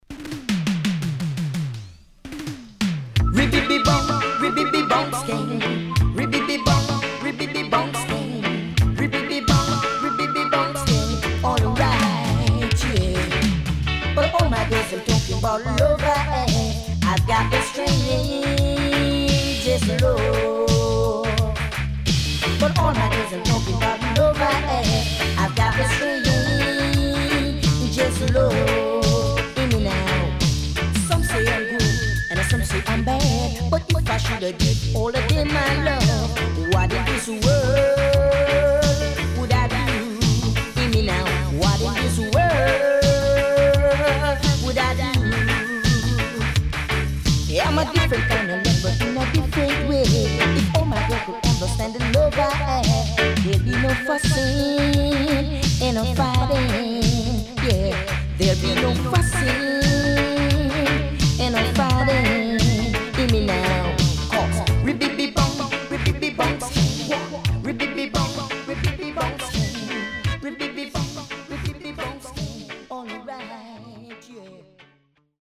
HOME > Back Order [DANCEHALL LP]
SIDE A:少しチリノイズ入りますが良好です。